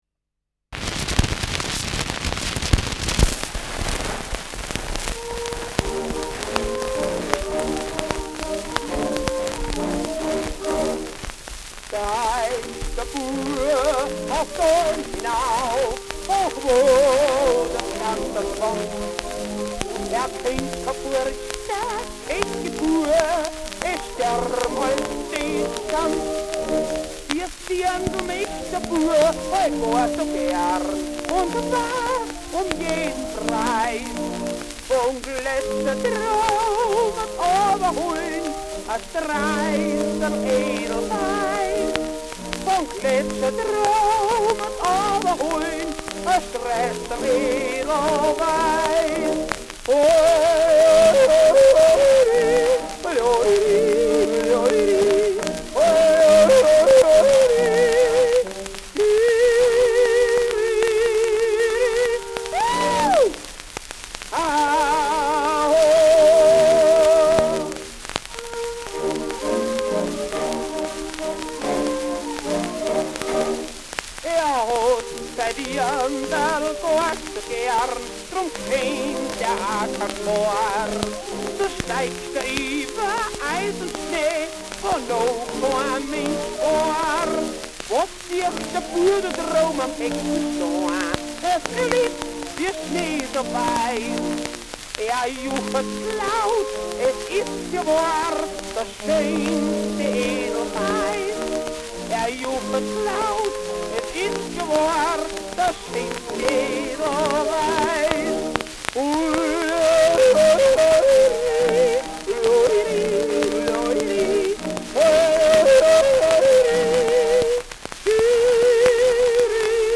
Schellackplatte
Stark abgespielt : Starkes Grundrauschen : Durchgehend leichtes bis stärkeres Knacken
[unbekanntes Ensemble] (Interpretation)
Jodellied mit dem Textanfang „Steigt der Bua auf d’ Alm nauf“.
Jodeling song with Orchestra
mit Orchesterbegleitung